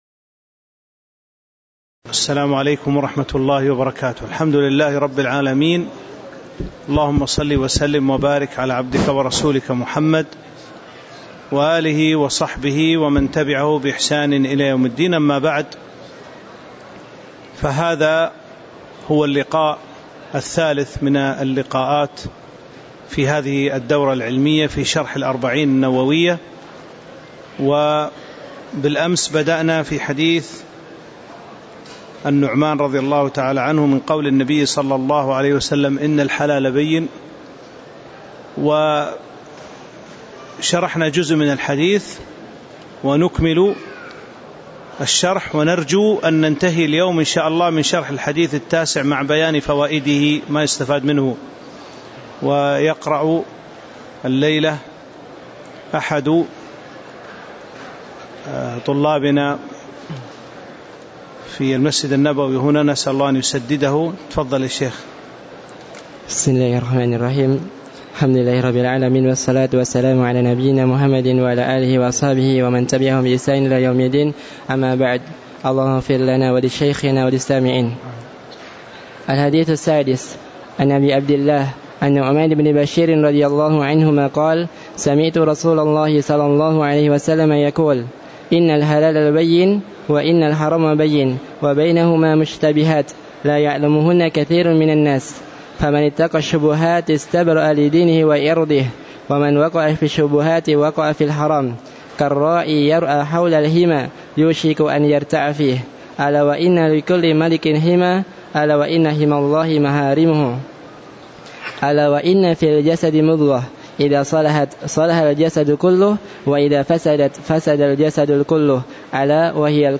تاريخ النشر ١٣ محرم ١٤٤٥ هـ المكان: المسجد النبوي الشيخ